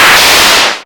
RADIOFX 10-R.wav